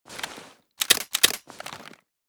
protecta_close.ogg.bak